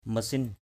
/mə-sin/